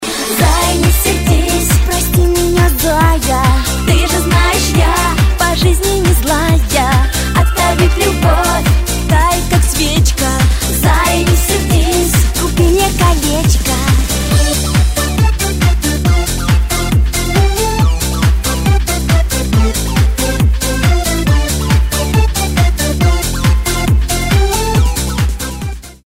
• Качество: 128, Stereo
веселые